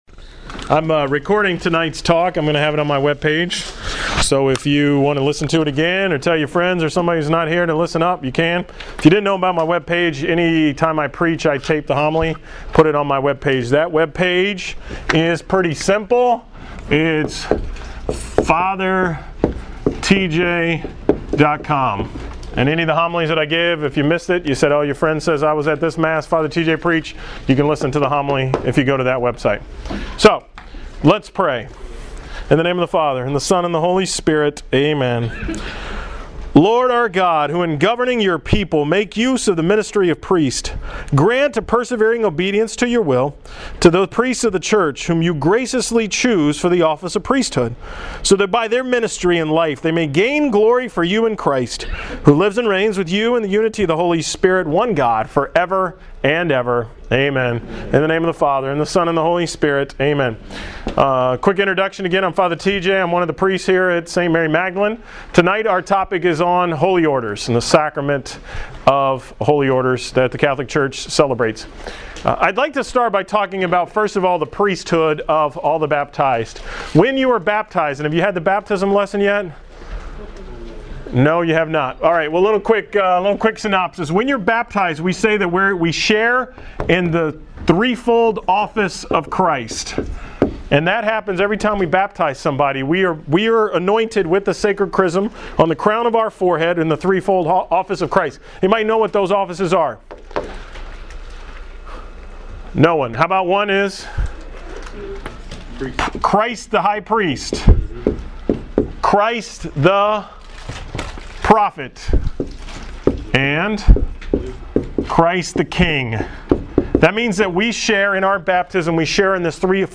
RCIA Talk on Holy Orders
From the RCIA class on Thursday, January 24th (This is a 40 minute talk) RCIA talk Holy Orders